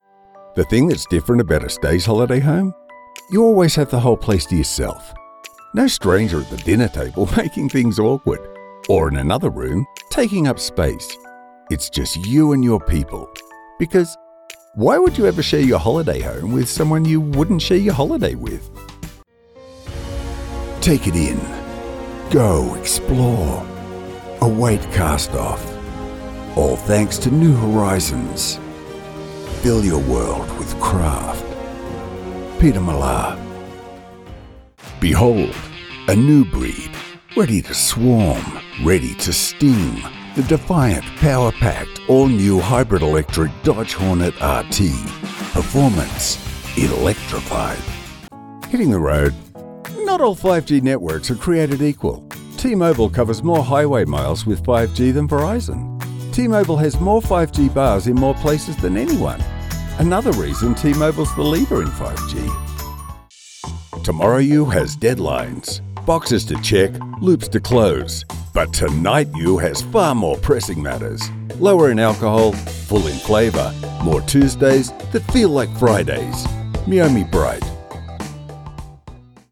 I perform recordings daily in my specialized, top-tier voiceover studio.
Voiceover Demos
Commercial Demo